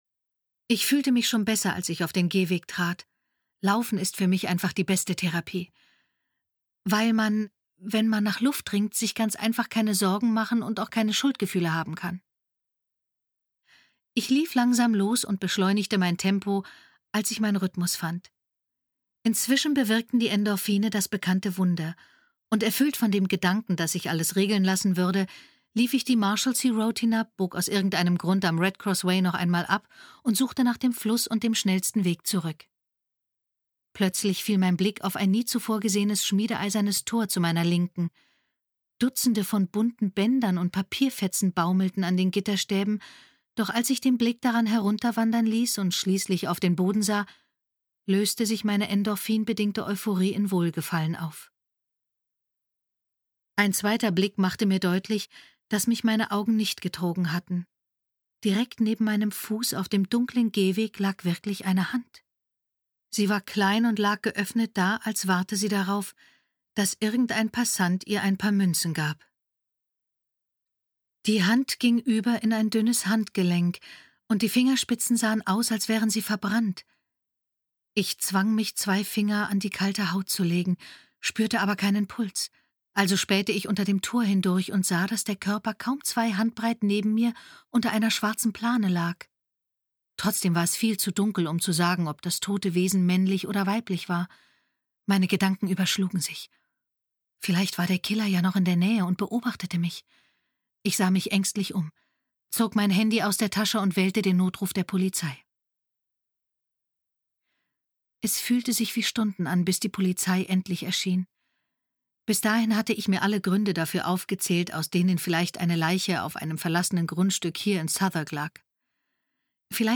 Hörbuch Print